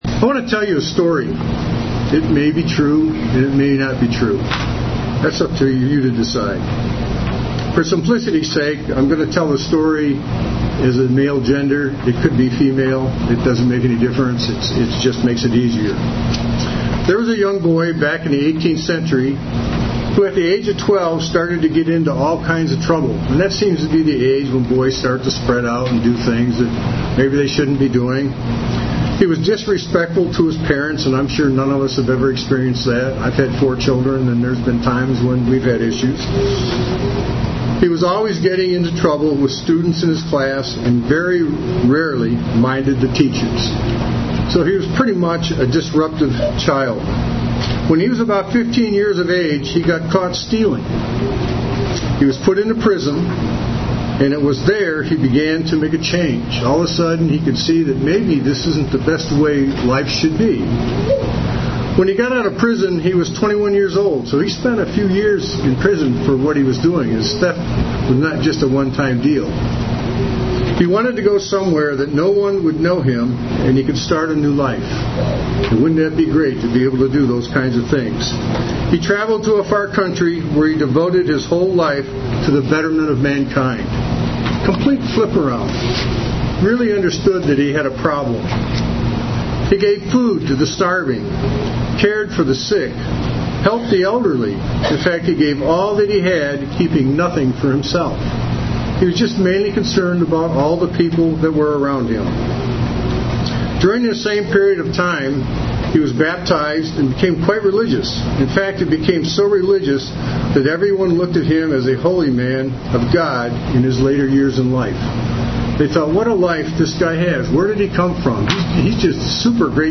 Given in Cincinnati North, OH
UCG Sermon Studying the bible?